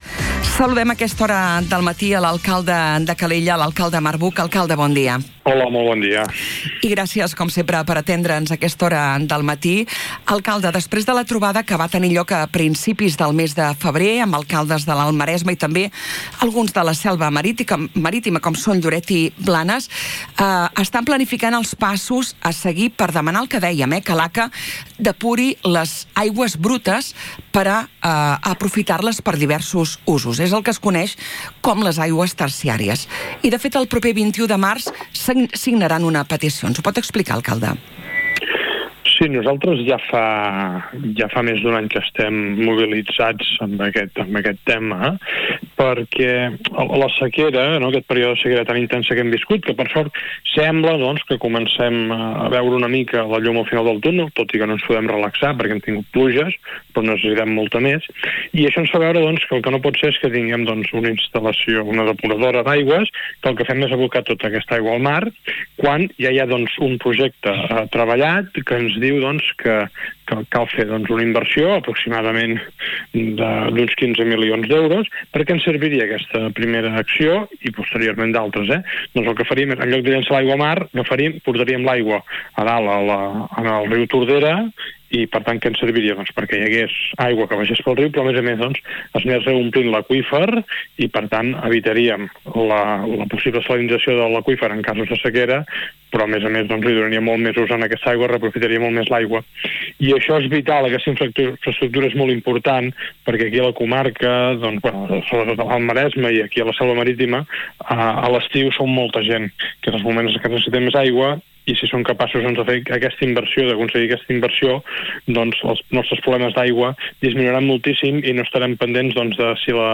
A l’entrevista al magazine a l’FM i +, també s’ha posicionat al costat del govern català en la decisió de mantenir les restriccions tot i la recuperació dels cabals dels rius després de les pluges dels últims dies.
A continuació podeu recuperar íntegrament l’entrevista a l’alcalde Marc Buch: